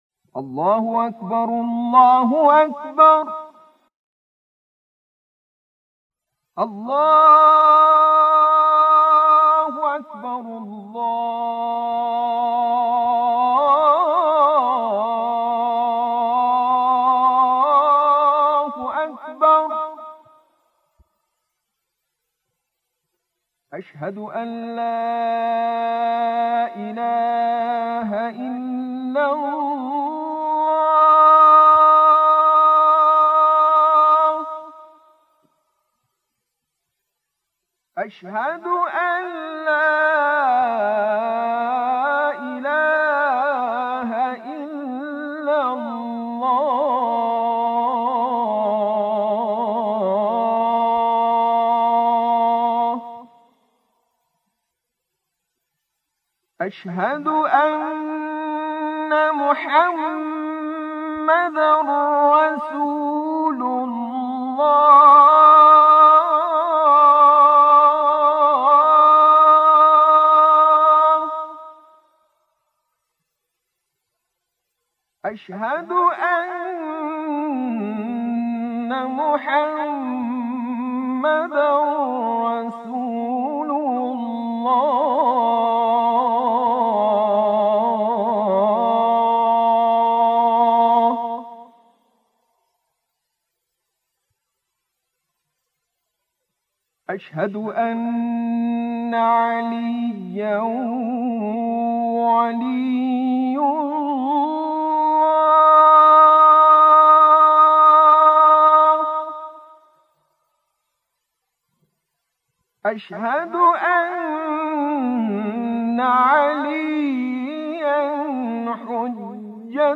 بدون آمادگی قبلی و بدون ساخت و تنظیم نغمات، وارد استودیو شده و در یک برداشت، این اذان را اجرا کرده
اذان